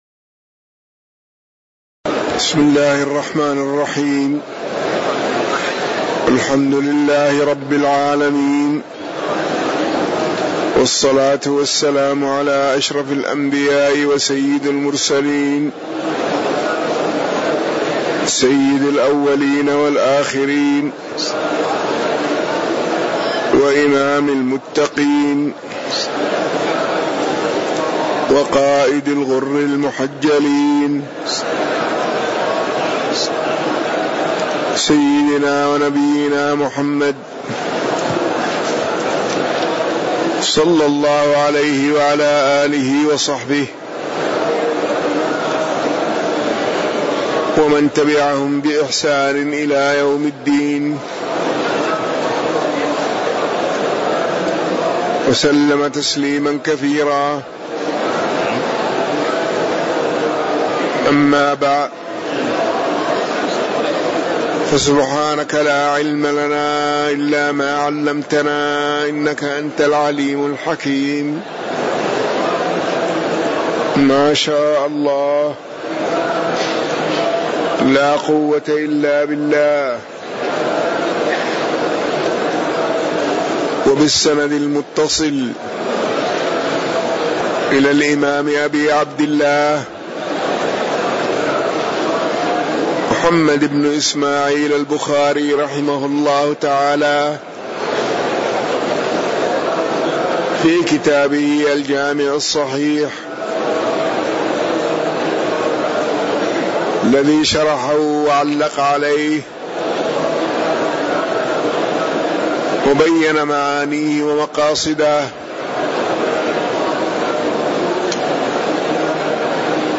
تاريخ النشر ١٤ ربيع الثاني ١٤٣٩ هـ المكان: المسجد النبوي الشيخ